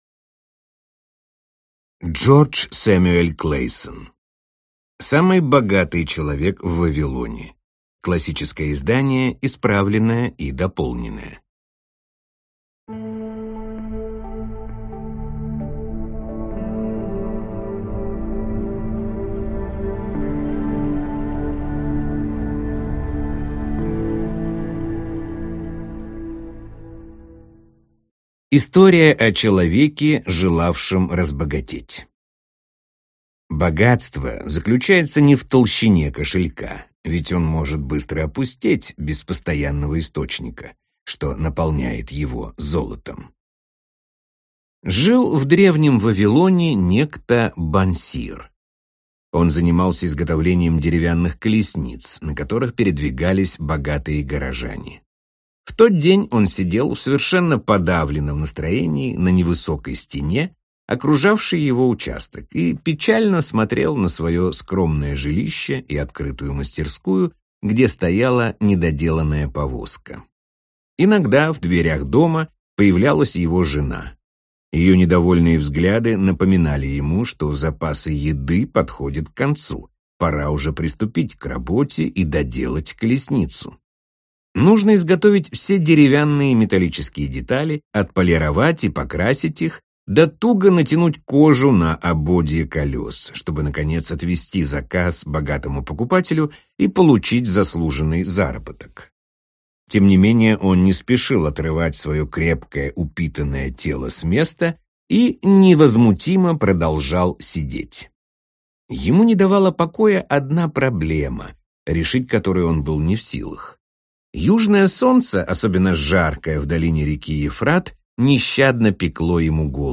Аудиокнига Самый богатый человек в Вавилоне.